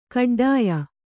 ♪ kaḍḍāya